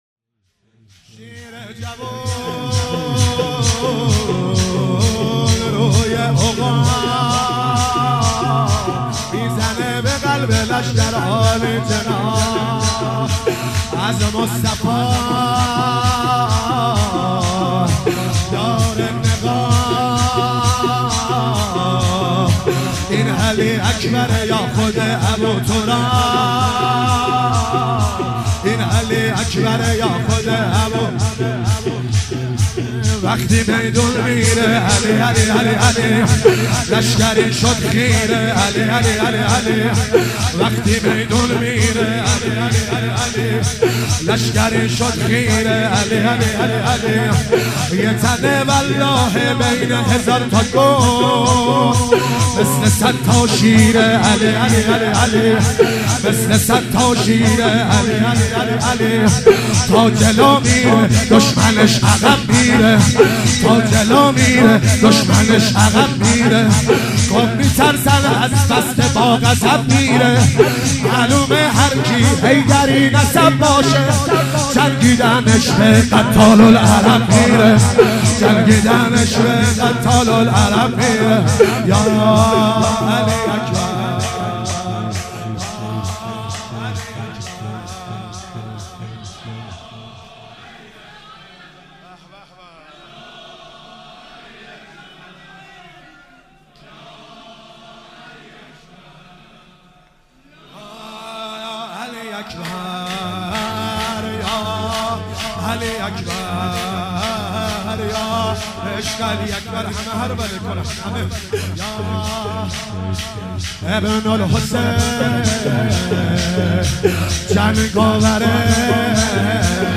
شب هشتم محرم 97 - شور - شیر جوون روی عقاب